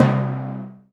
ORCH TIMY3-S.WAV